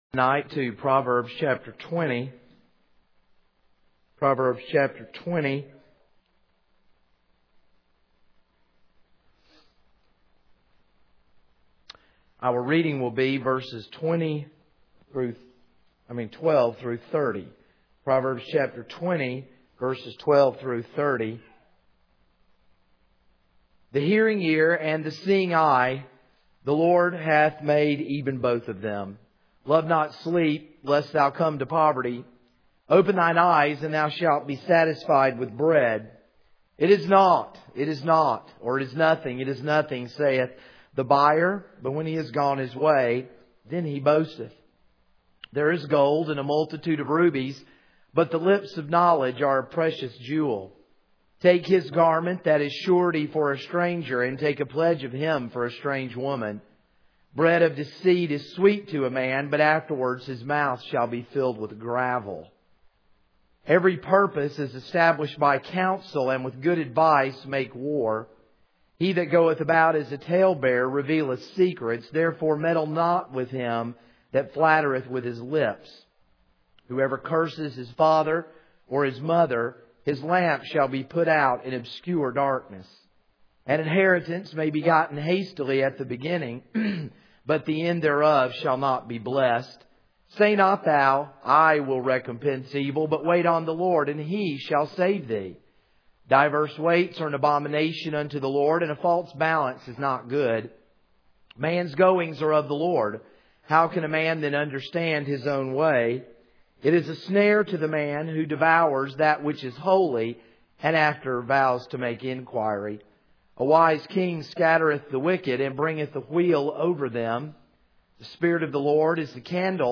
This is a sermon on Proverbs 20:12-30.